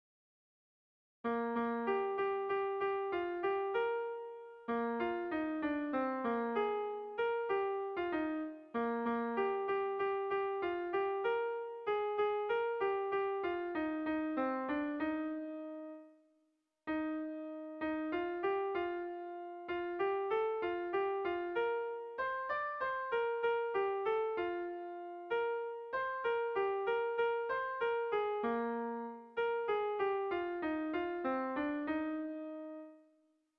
Zortziko handia (hg) / Lau puntuko handia (ip)
A1A2BD